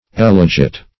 Elegit \E*le"git\, n. [L., he has chosen, fr. eligere to choose.